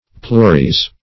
Pluries \Plu"ri*es\, n. [So called from L. pluries many times,